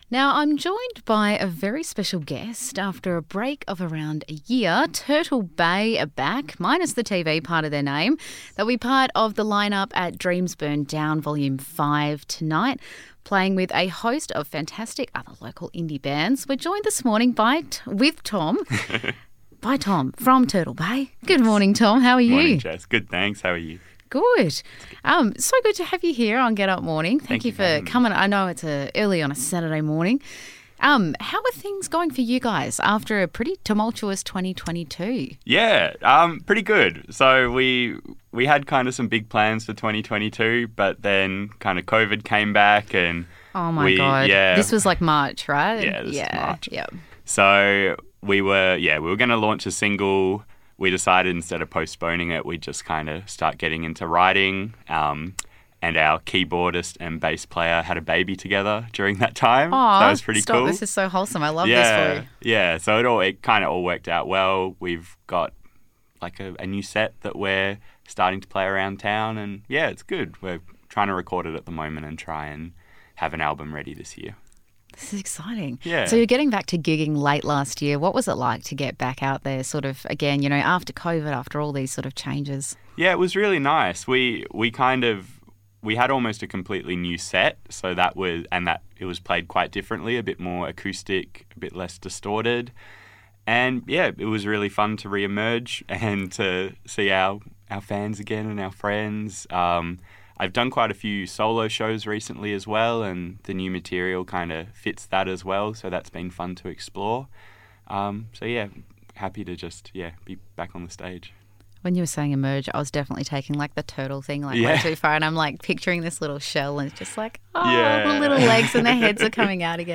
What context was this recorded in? to discuss the band’s recent pause, chat about forthcoming gigs and give us a live preview of their new music.